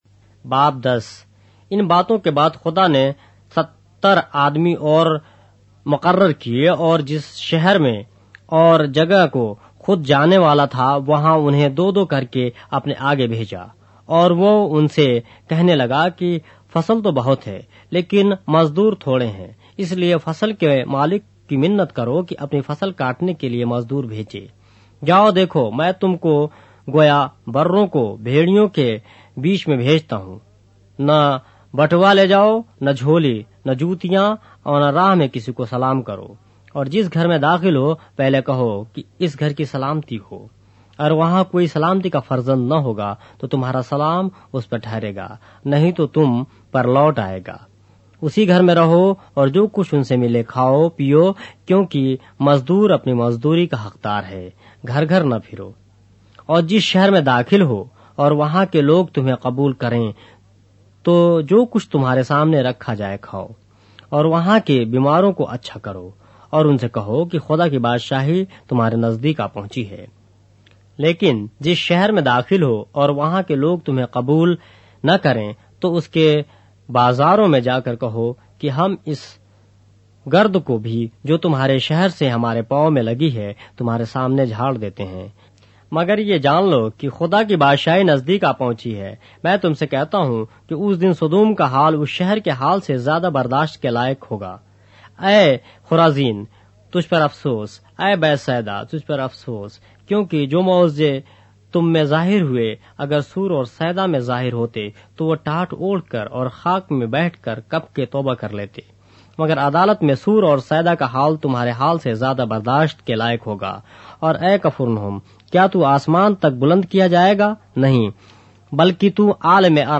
اردو بائبل کے باب - آڈیو روایت کے ساتھ - Luke, chapter 10 of the Holy Bible in Urdu